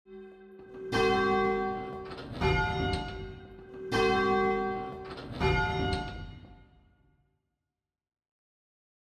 Major 7th Intervals
As a result, the sound waves only align about 9% of the time and we hear the interval as very dissonant.